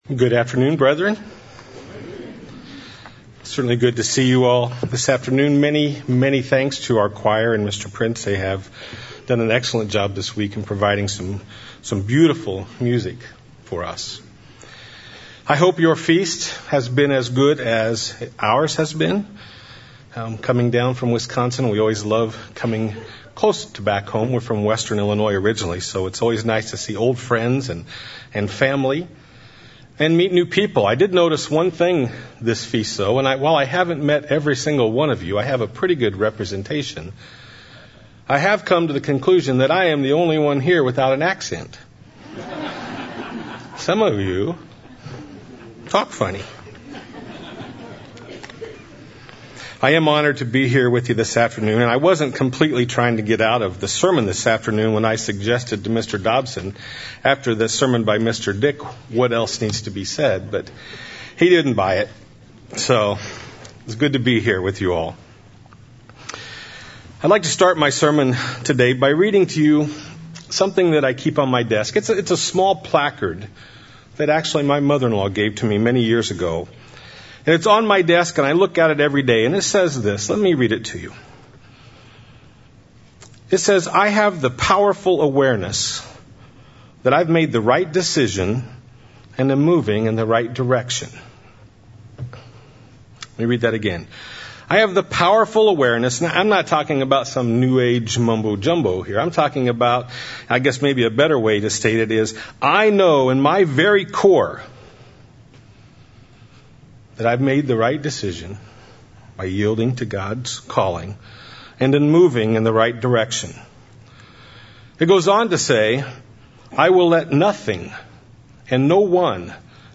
This is the last sermon of the series for the Feast. The speaker emphasizes that courage, honor and relying on God are important Christian attributes. He goes on to give an example of four courageous figures during the life of David who were used by God to achieve great victories over Israel's enemies.
This sermon was given at the Branson, Missouri 2018 Feast site.